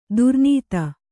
♪ durnīta